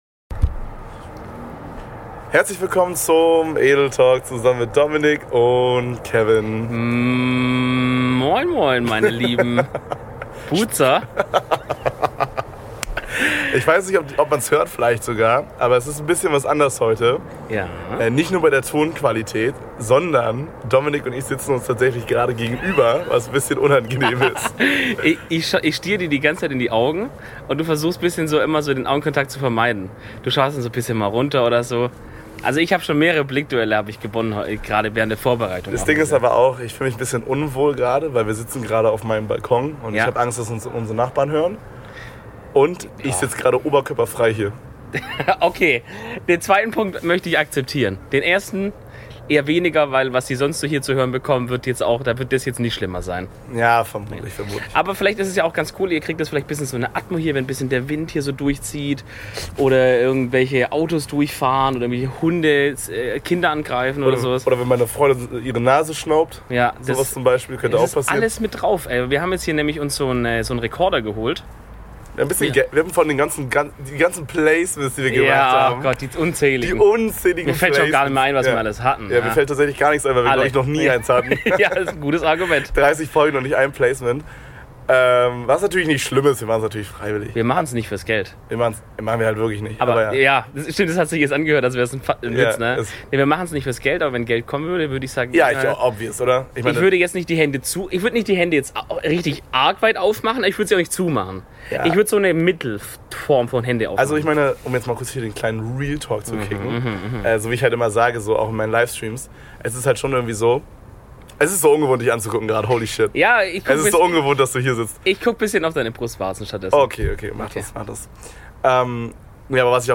Genießt also diese erste Outdoor-Folge!